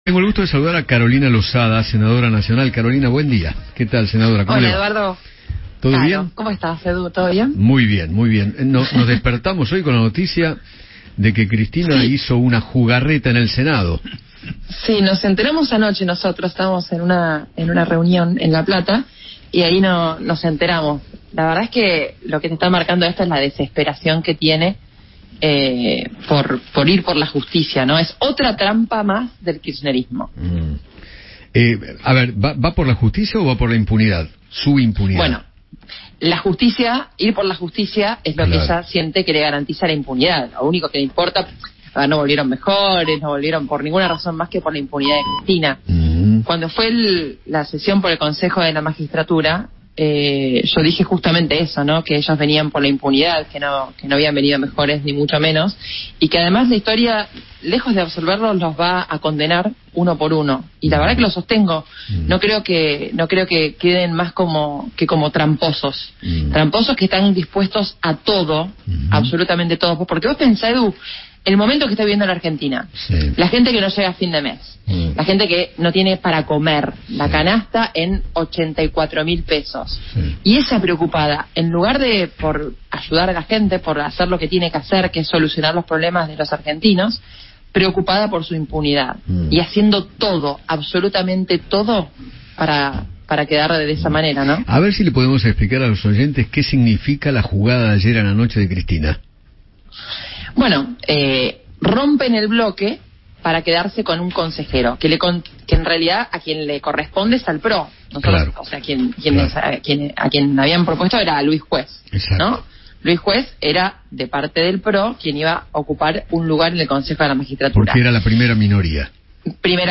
Carolina Losada, senadora de Juntos por el Cambio, dialogó con Eduardo Feinmann sobre la decisión de Cristina Kirchner de partir su bloque en el Senado para convertirse en la segunda minoría que le permita al oficialismo poner un representante en el Consejo de la Magistratura.